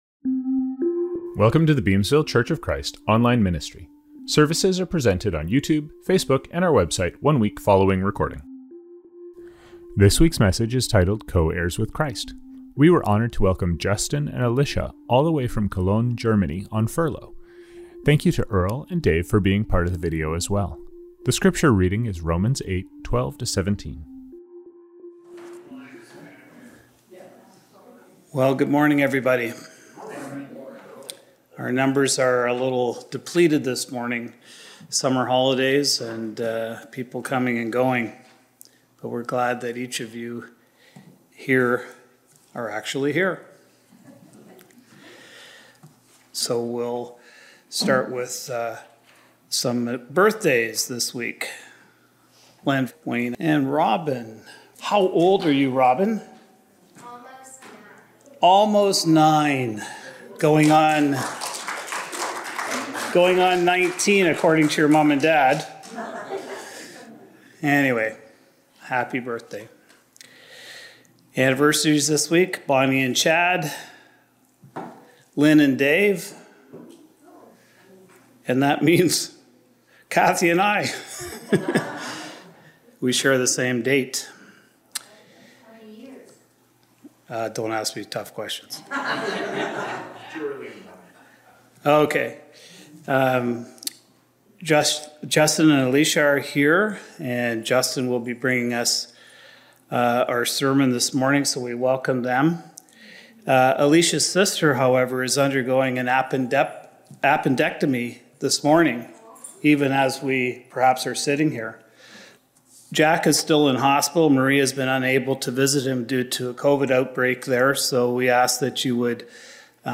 Reading: Romans 8:12-17.